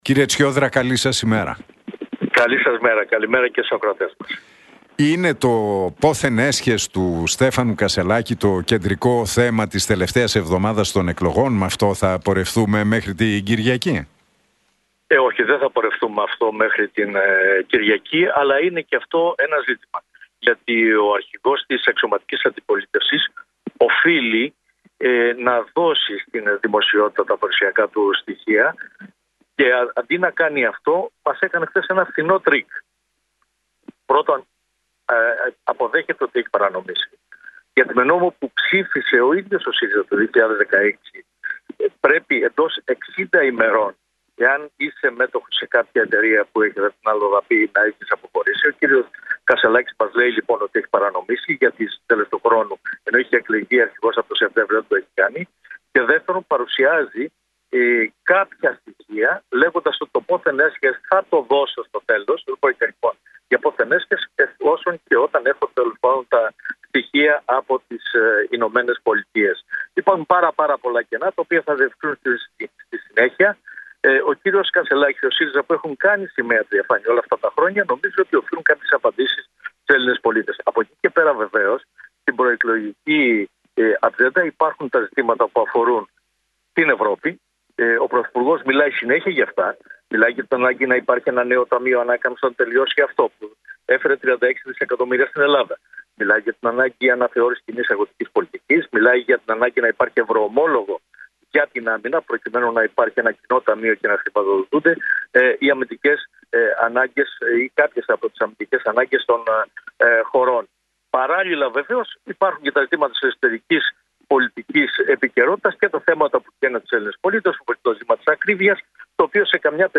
Πρώτον παραδέχεται ότι έχει παρανομήσει, γιατί με νόμο που ψήφισε ο ΣΥΡΙΖΑ το 2016 πρέπει εντός 60 ημερών αν είσαι μέτοχος σε κάποια εταιρεία στην αλλοδαπή» δήλωσε ο Δημήτρης Τσιόδρας μιλώντας στην εκπομπή του Νίκου Χατζηνικολάου στον Realfm 97,8.